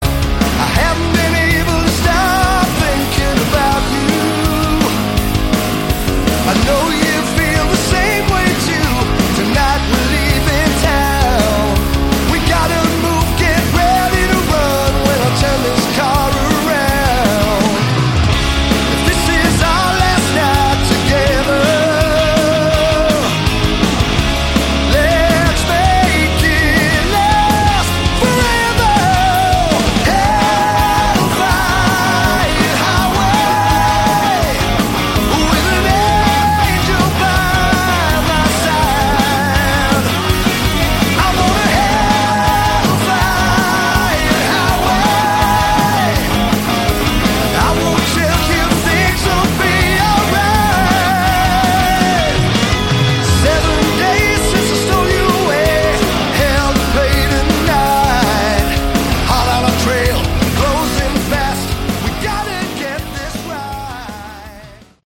Category: AOR
lead vocals, bass
drums
guitars, keyboards
A really nice combination of AOR and Melodic Hardrock.